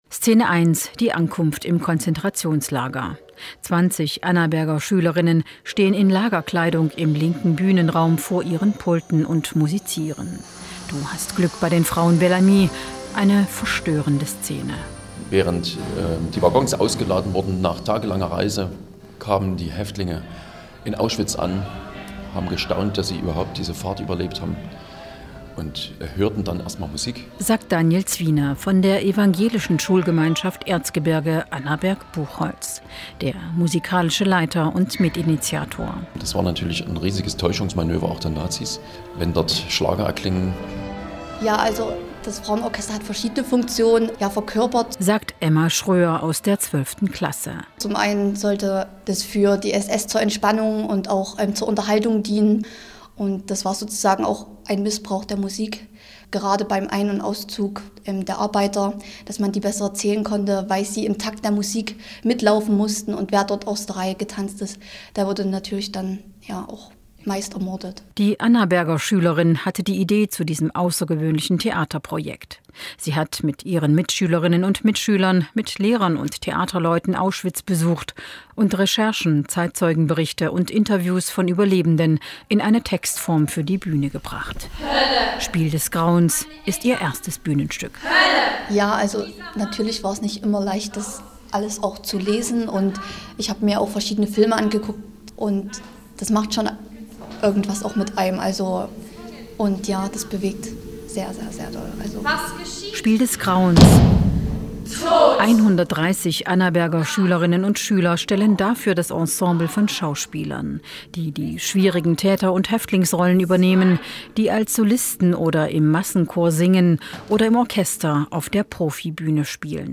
Hier noch 2 Radiobeiträge